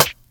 Snare (Breathe In Breathe Out).wav